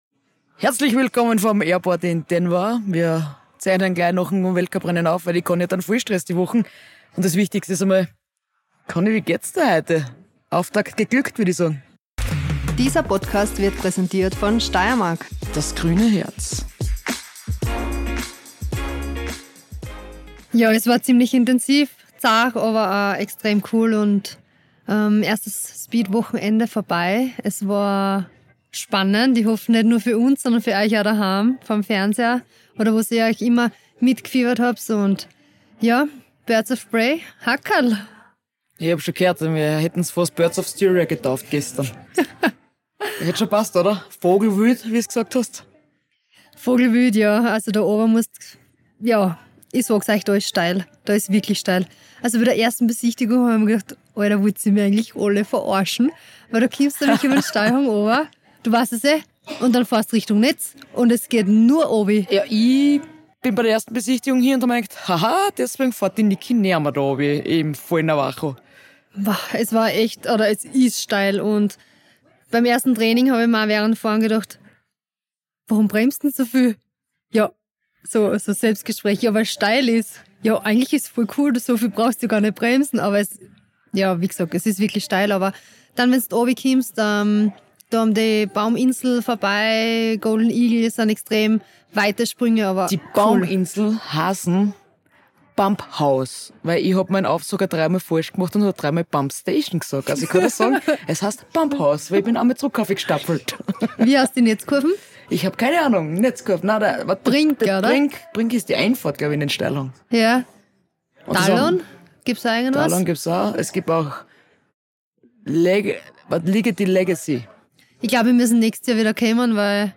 Jede Minute wird genützt - „wos dahinter steckt“ direkt vom Flughafen ~ Wos dahinter steckt Podcast
Diese haben wir in Denver genützt, um das Rennwochenende von Beaver Creek revuepassiveren zu lassen und über die Erfolge des Teams, das Material und die Strecke zu plaudern.